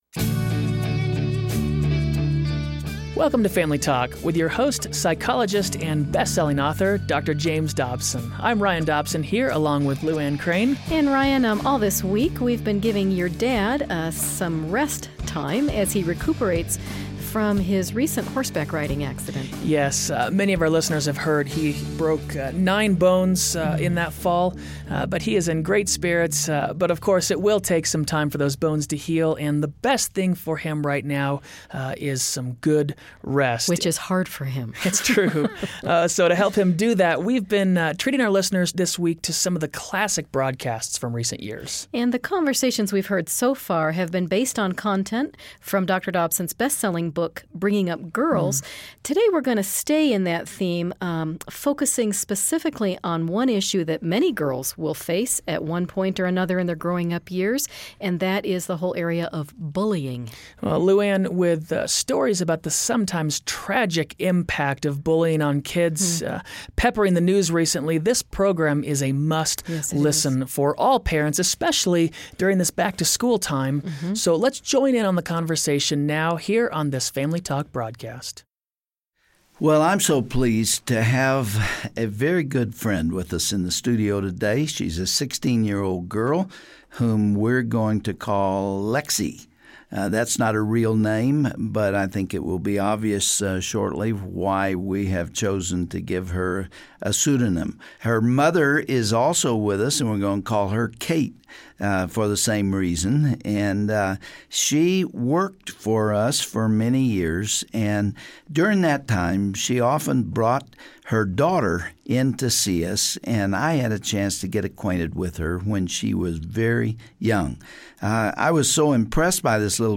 Host Dr. James Dobson
Panel of children & parents